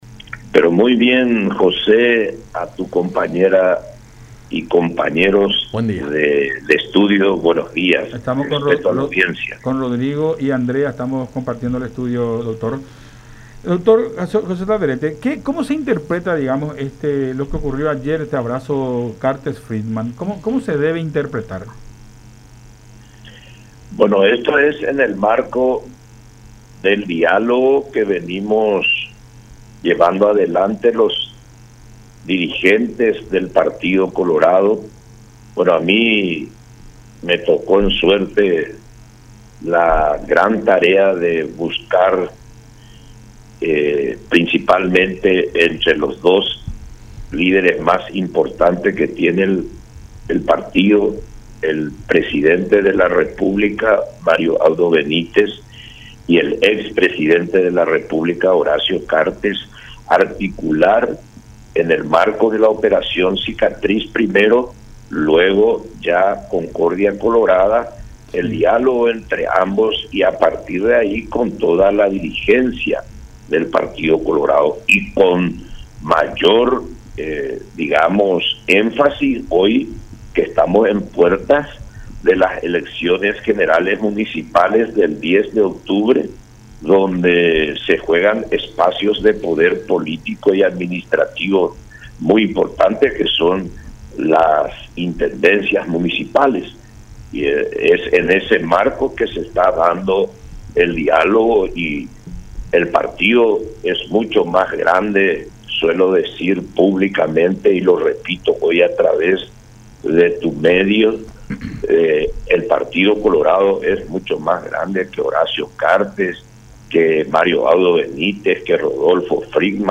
Ahora entre Horacio Cartes y Rodolfo Friedmann”, resaltó Alderete en diálogo con Enfoque 800 a través de La Unión.